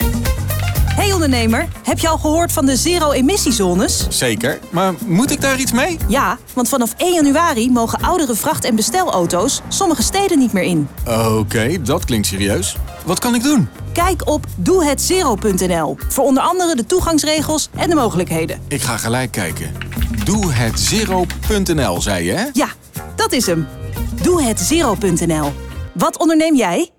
Radiocommercial doehetzero